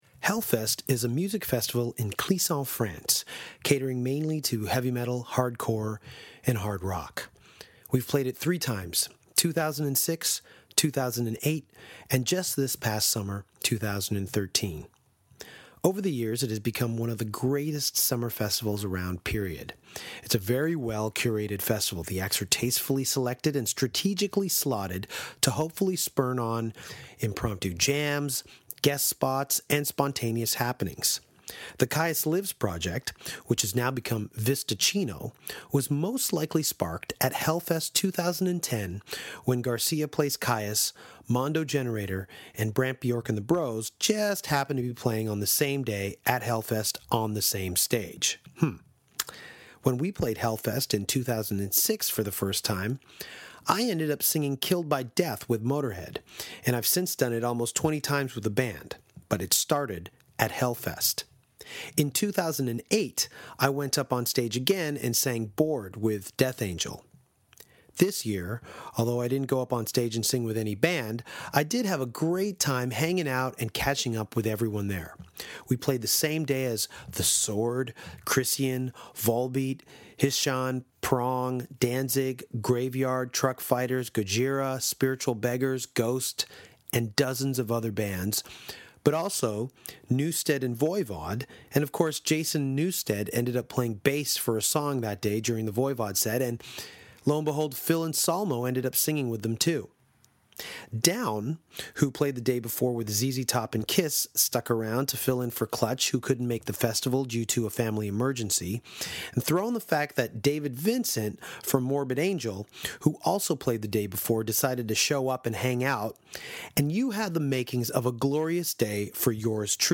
Per Wiberg (Spiritual Beggars/Mojobone/King Hobo/ex-Opeth) met up with Danko during 2013’s Hellfest in France for a sit-down session where they talked about Ihsahn, The Roadburn Festival, Candlemass, Whitesnake, Urban Dance Squad and Corrosion Of Conformity.